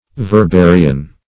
Verbarian \Ver*ba"ri*an\, a.